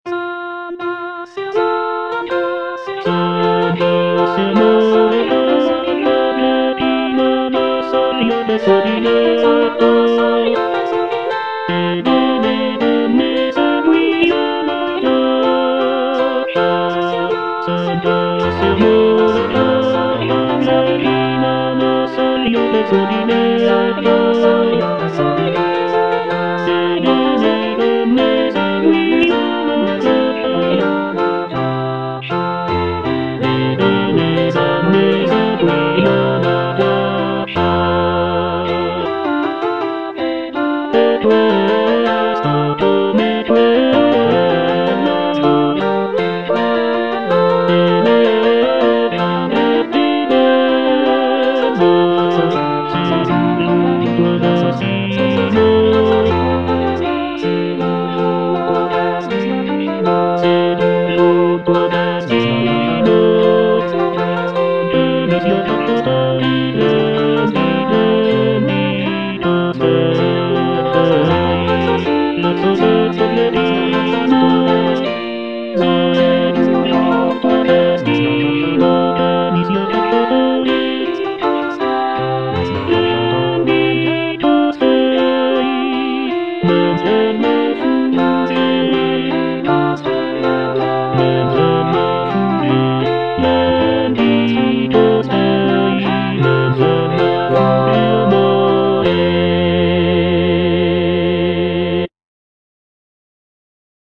C. MONTEVERDI - S'ANDASSE AMOR A CACCIA Tenor (Emphasised voice and other voices) Ads stop:
"S'andasse Amor a caccia" is a madrigal composed by Claudio Monteverdi, an Italian composer from the late Renaissance period.